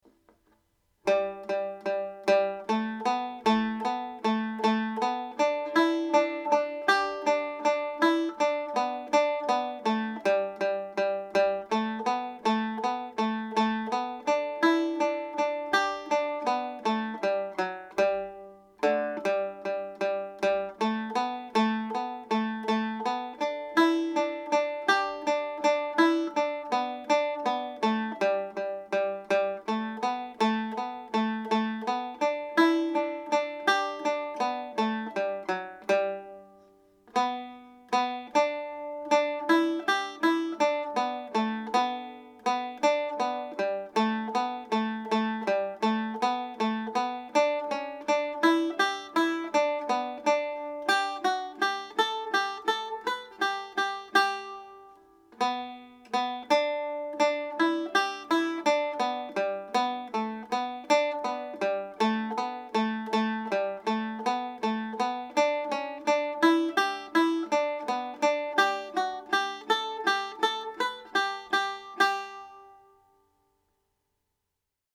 full tune